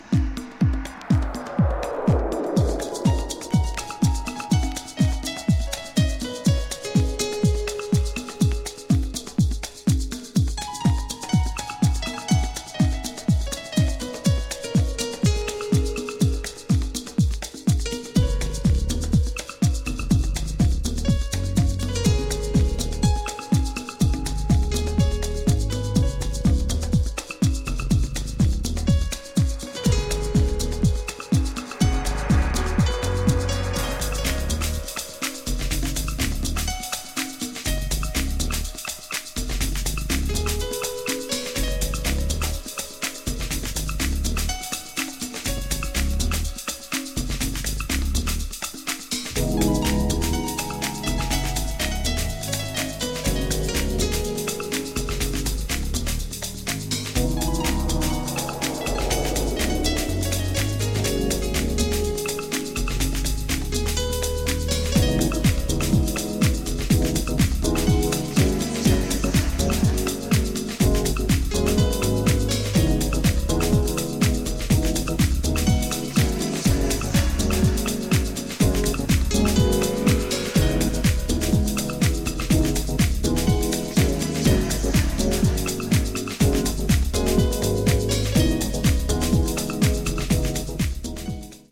Vocal Mix
Dub Mix